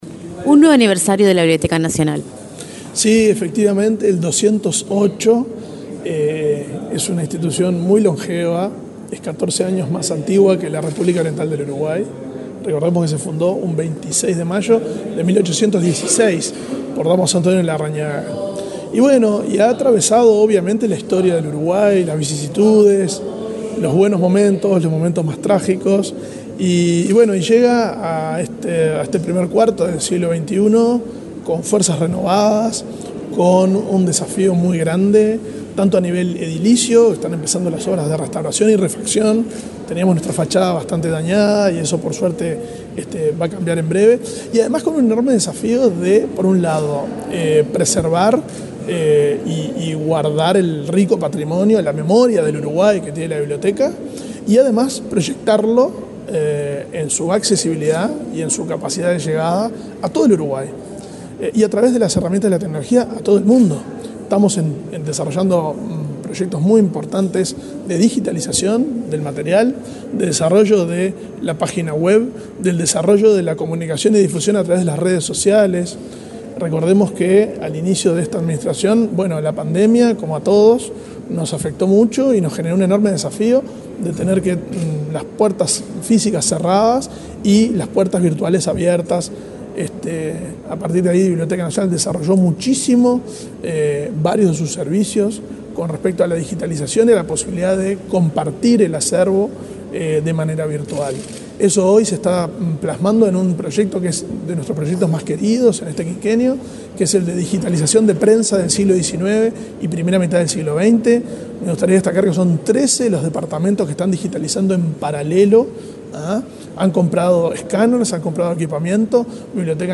Entrevista al director de la Biblioteca Nacional, Valentín Trujillo
Este lunes 27, el director de la Biblioteca Nacional, Valentín Trujillo, dialogó con Comunicación Presidencial, luego de participar en el acto central